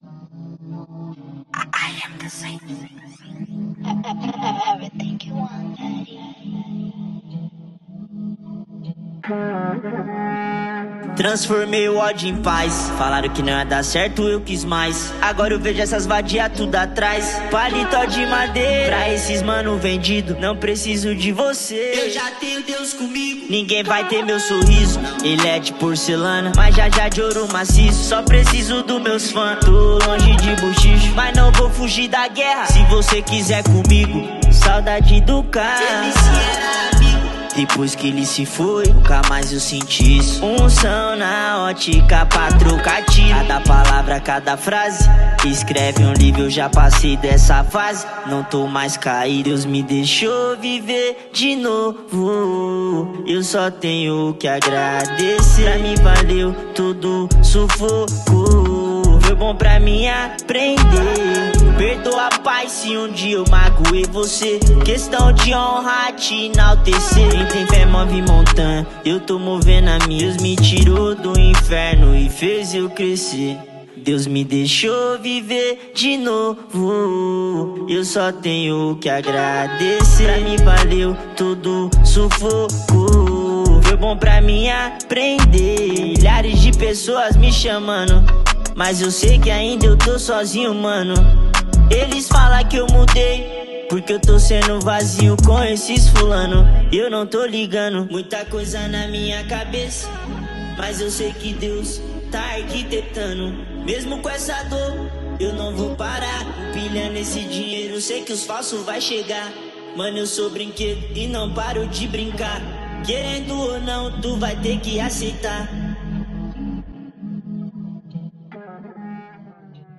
2024-11-22 09:09:48 Gênero: Funk Views